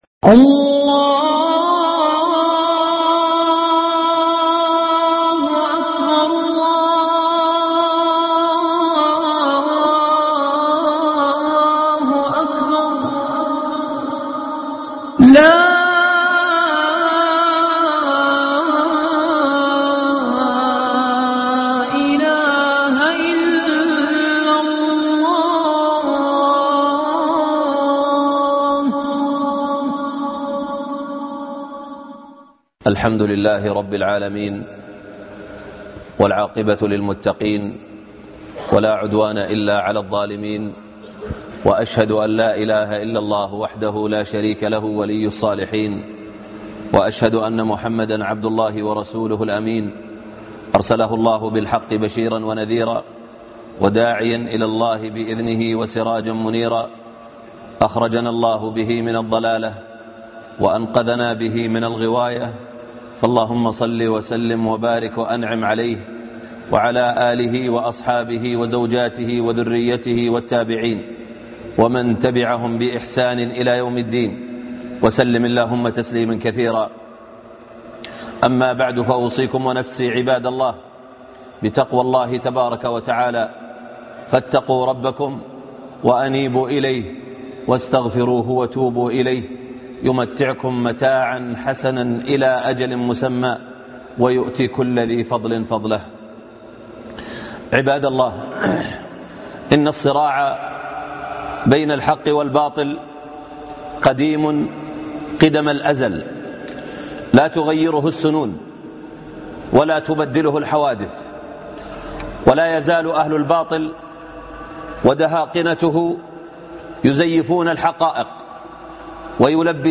لماذا الحرب على الاسلام ( خطب الجمعة